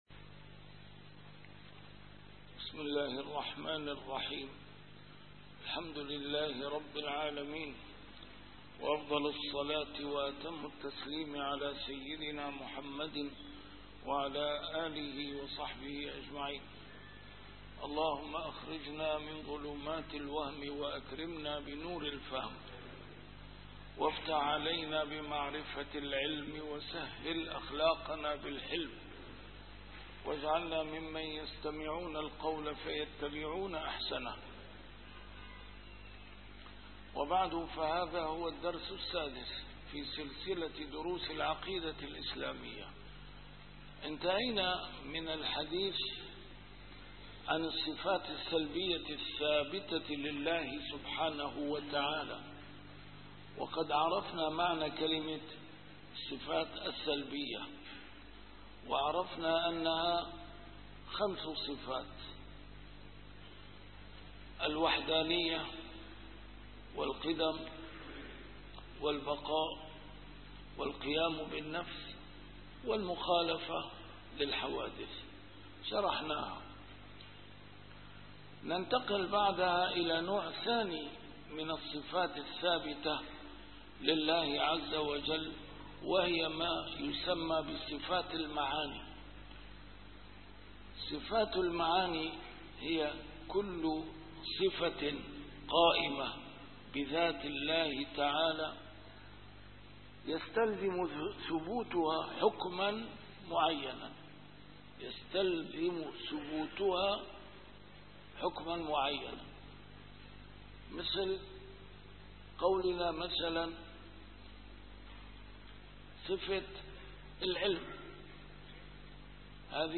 A MARTYR SCHOLAR: IMAM MUHAMMAD SAEED RAMADAN AL-BOUTI - الدروس العلمية - كبرى اليقينيات الكونية - 6- الصفات المعنوية: (العلم، الإرادة، القدرة، السمع، البصر، الكلام)